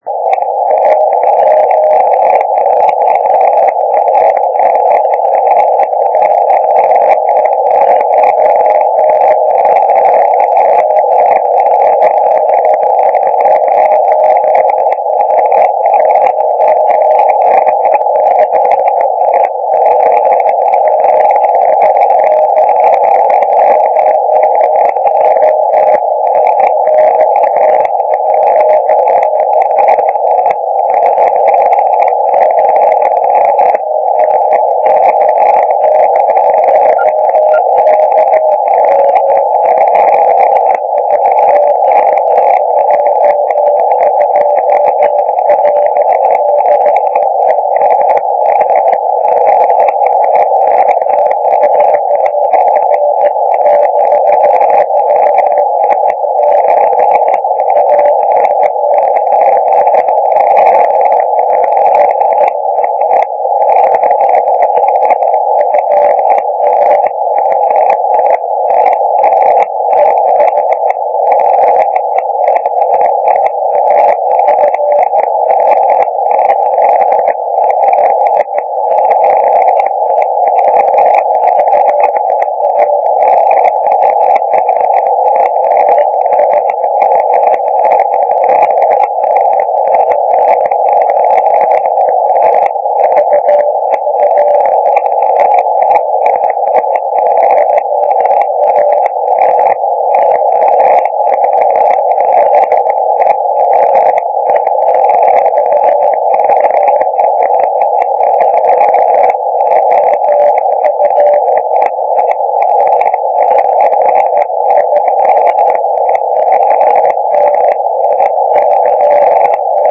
Všimněte si, jak se stoup�n�m bal�nu, byl sign�l st�le kvalitnějš�.